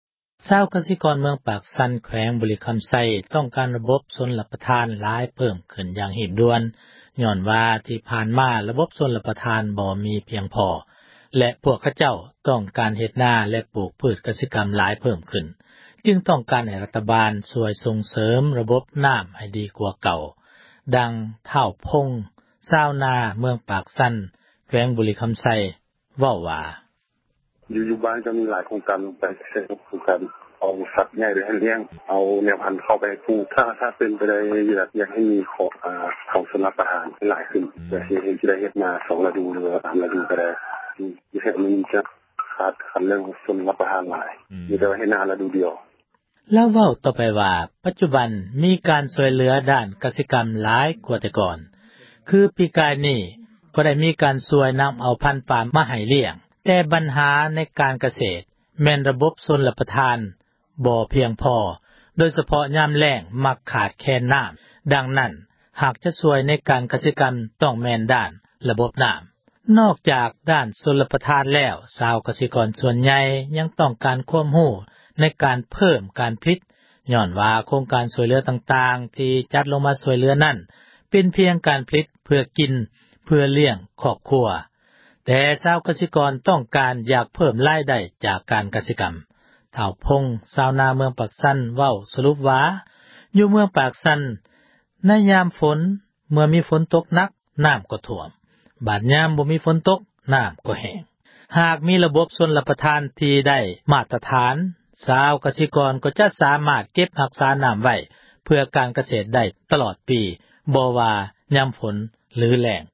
ປາກຊັນຕ້ອງການ ຣະບົບຊົລປະທານ — ຂ່າວລາວ ວິທຍຸເອເຊັຽເສຣີ ພາສາລາວ